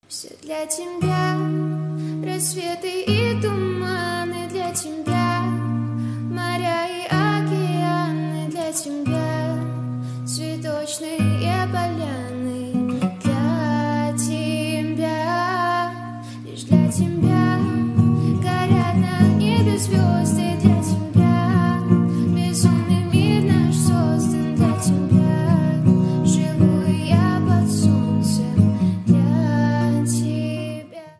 Гитара
поп